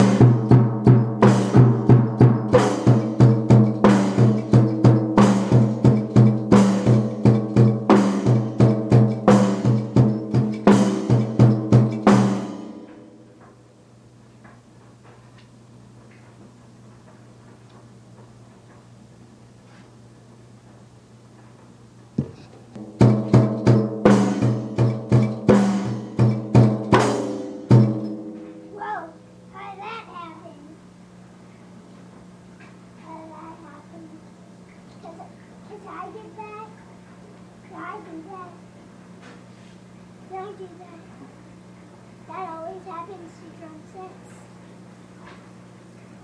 My drums